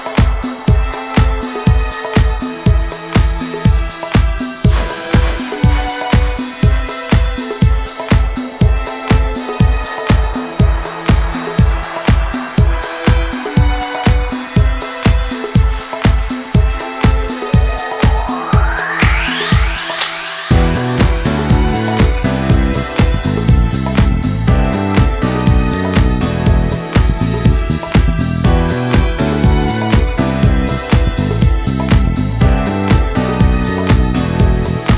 Жанр: Танцевальные / Хаус
Dance, House